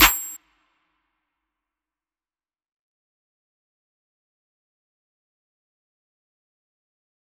DMV3_Clap 9.wav